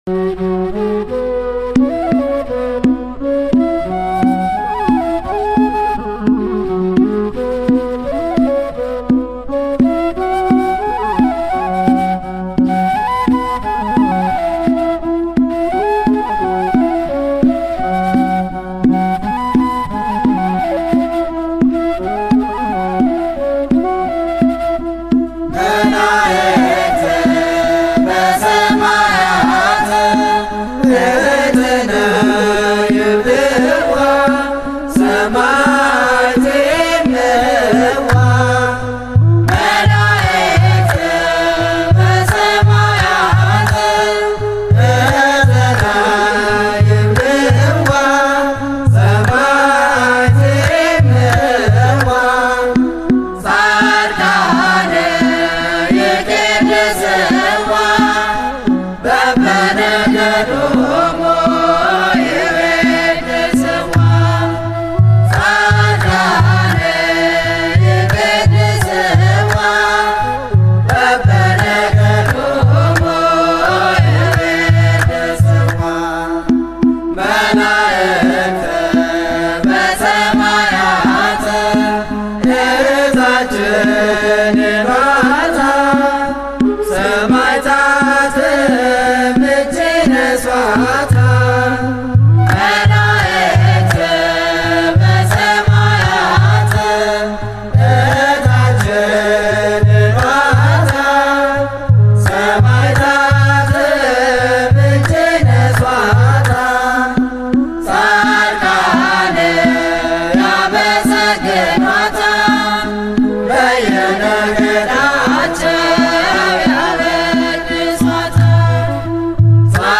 Re'ese Adbarat Tserha Aryam Kidist Selassie Cathedral Ethiopian Orthodox Tewahedo Church --- Minneapolis, Minnesota